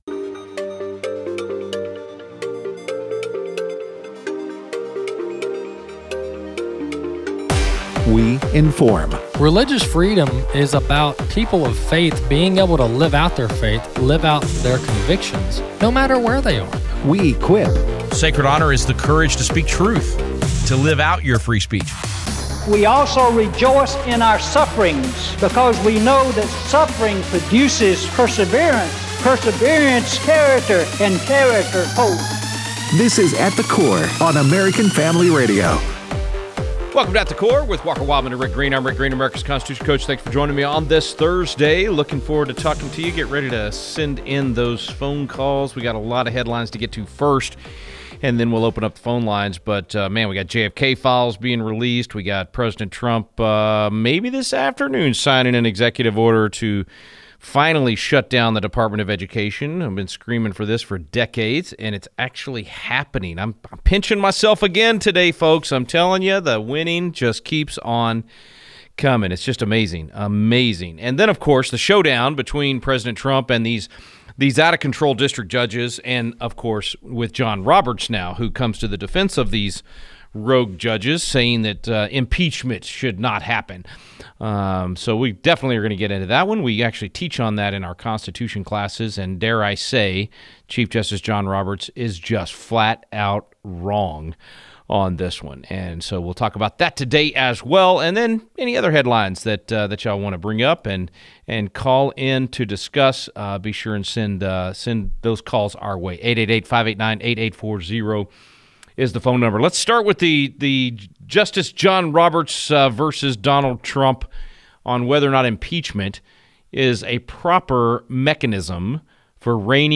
Callers share